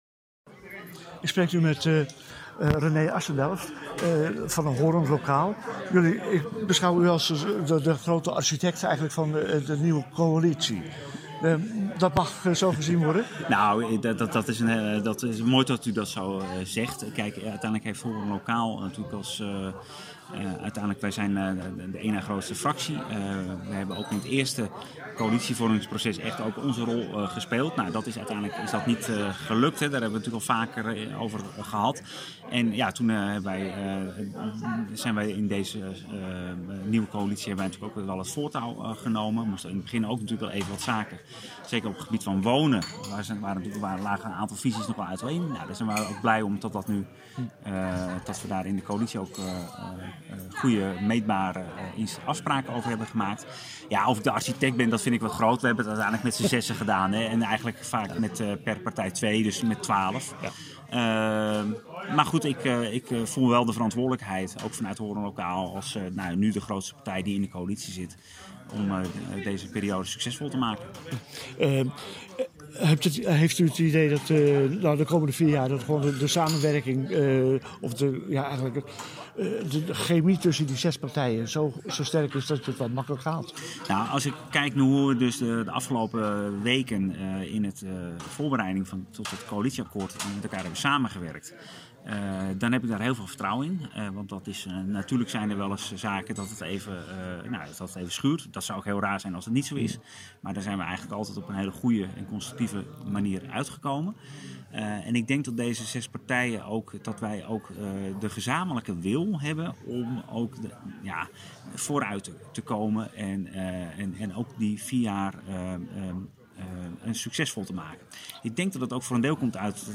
Raadsvergadering 21 juni 2022 (Audio interview met Arnica Gortzak (PvdA), Rob Droste (VVD), wethouder René Assendelft (HL)